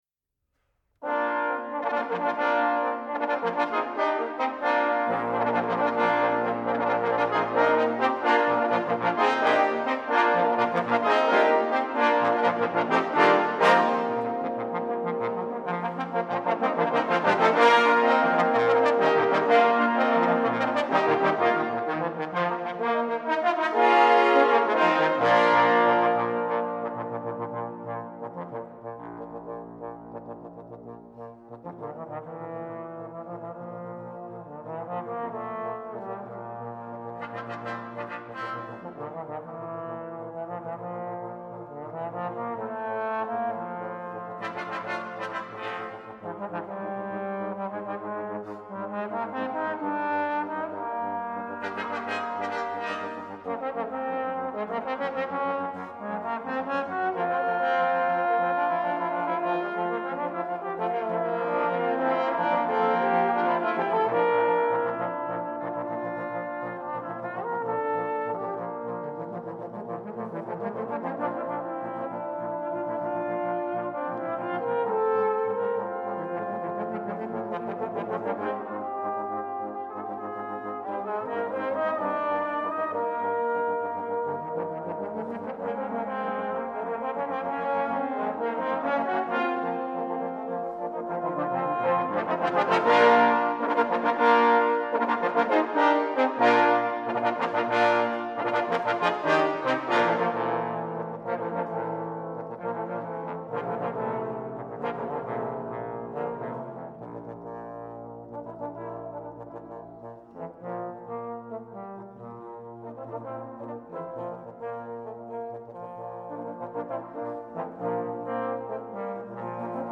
spectacular opening fanfare
Bb Soprano Sax
Trumpet in Bb 1
Euphonium in Bb
Timpani
Glockenspiel
Percussion